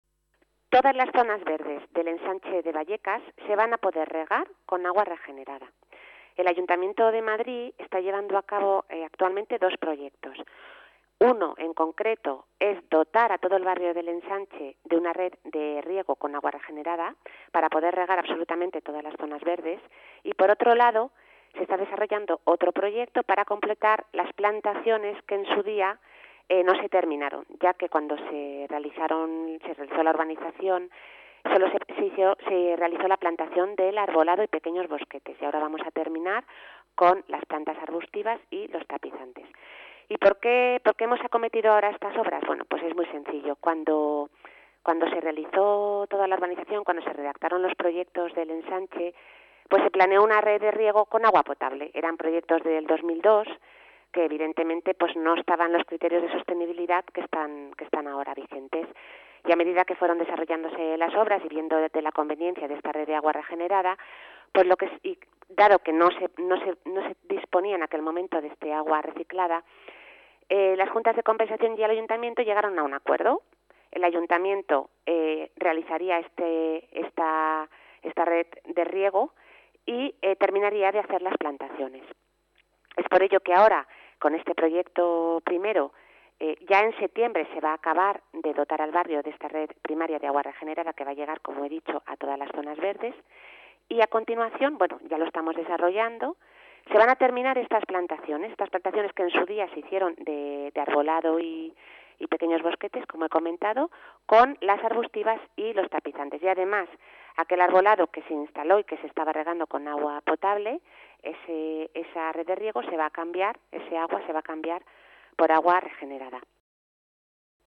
Nueva ventana:La directora general de Gestión del Agua y Zonas Verdes, Beatriz García San Gabino, explica los proyectos.